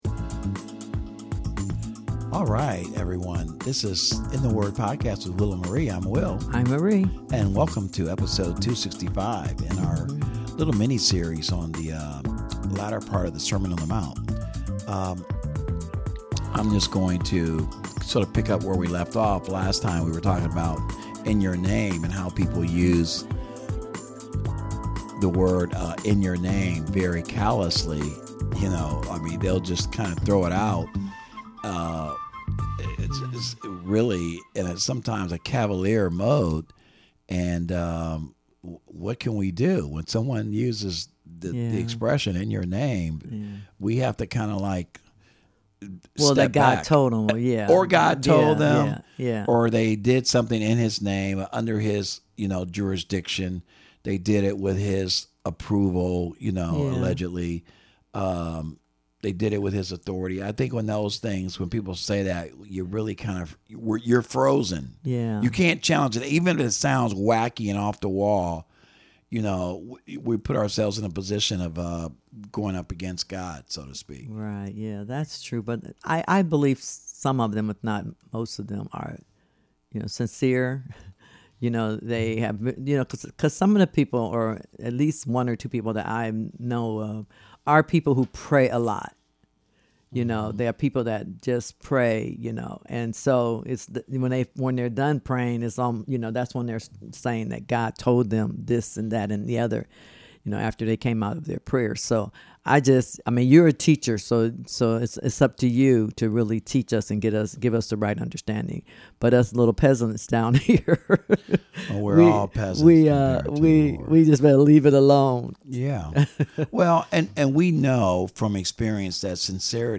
This is a discussion about the Bible, God’s Holy Word, and how it is relevant in current events. We analyze the importance of having a Biblical worldview and what that looks like in today’s society. Our goal is to unpack the truth of God’s Word and help believers apply that truth in their daily lives.